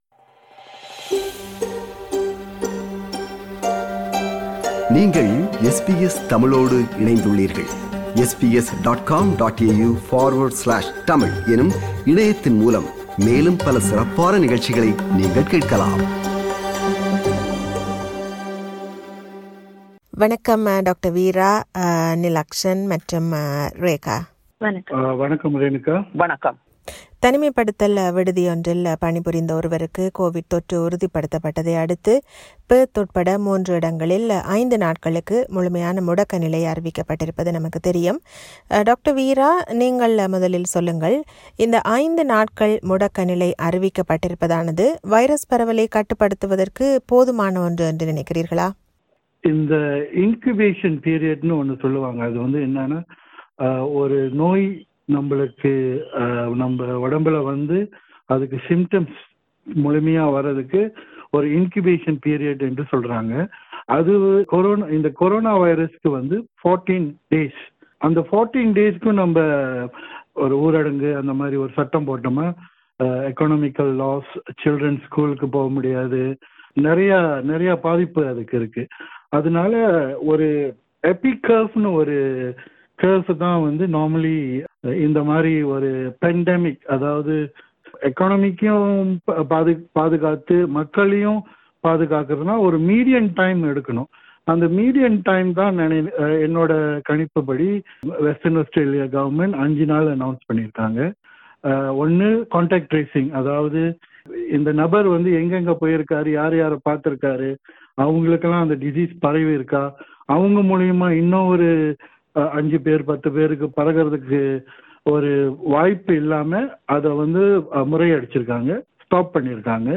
Western Australia has recorded no new COVID-19 cases for a third straight day as it passes the halfway point of a five-day lockdown. This is a panel discussion on Western Australia’s COVID outbreak.